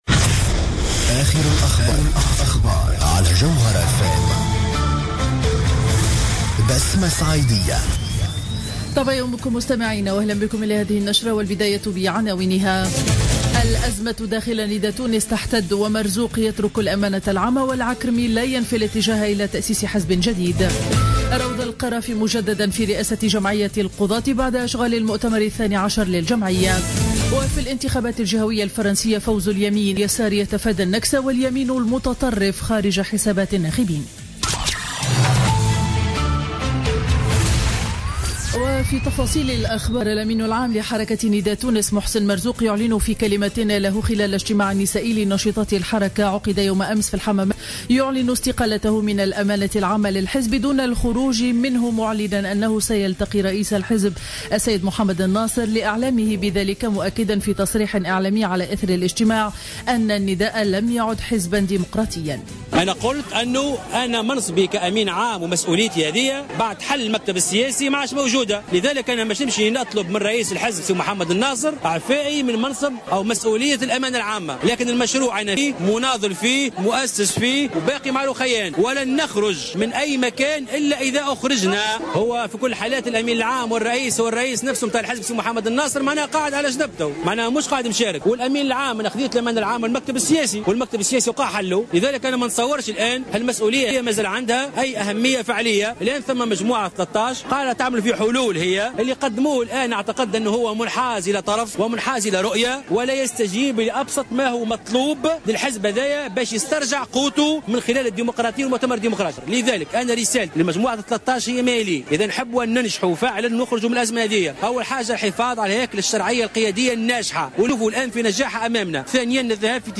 نشرة أخبار السابعة صباحا ليوم الاثنين 14 ديسمبر 2015